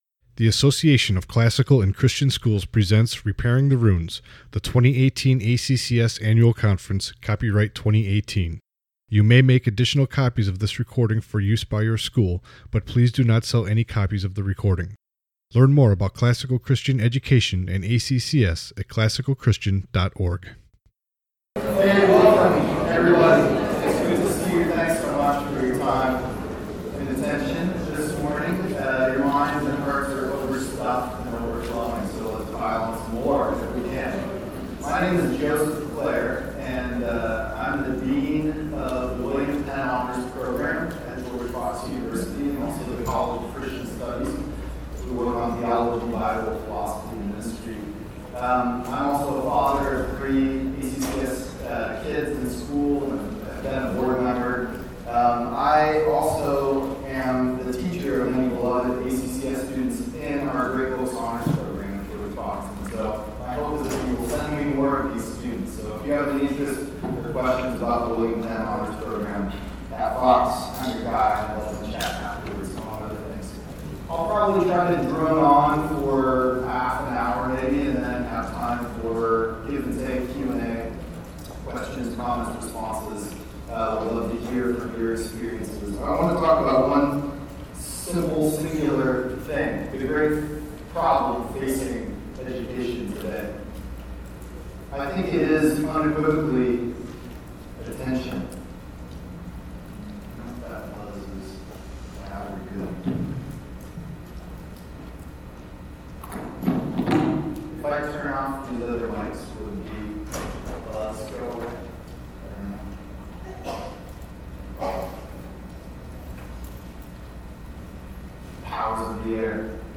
2018 Plenary Talk | 58:03 | All Grade Levels, Virtue, Character, Discipline